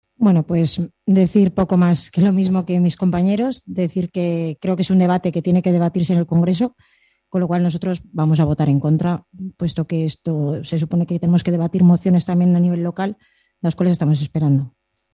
En el último pleno municipal se rechazó, con los votos en contra del equipo de gobierno tripartito y la abstención de Cs, una moción del PP en contra de la ocupación ilegal de viviendas, para instar al Gobierno central a que se puedan desalojar en 24 horas y no se permita empadronar a los ocupas en esas viviendas, entre otras cuestiones.
PR+, PSOE y Cs creen que el Ayuntamiento de Haro no es el sitio apropiado para debatir este asunto, que para eso está el Congreso de los Diputados, mientras que para el PP este tipo de situaciones también se pueden dar en Haro. Así lo destacaban Leopoldo García, Andrea Gordo, Fernando Castillo y Alberto Olarte.